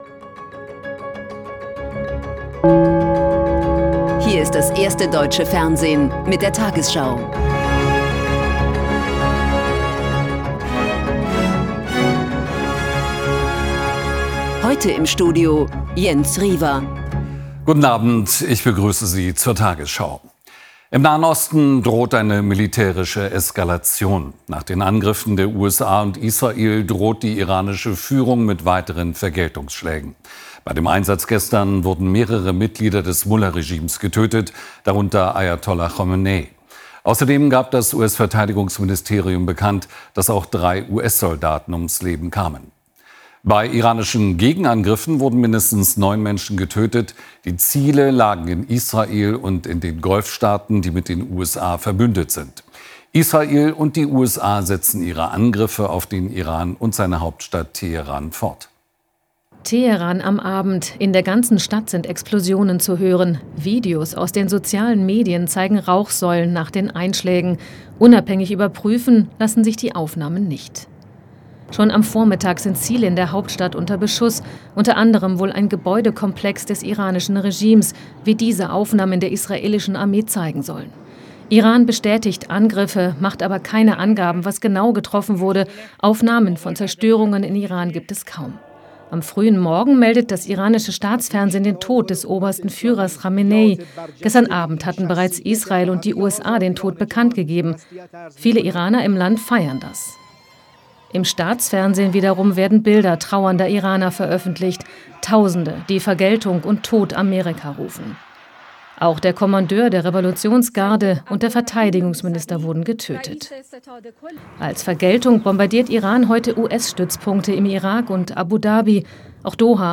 tagesschau 20:00 Uhr, 01.03.2026 ~ tagesschau: Die 20 Uhr Nachrichten (Audio) Podcast